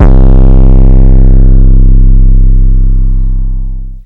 808 - Murda [ C ].wav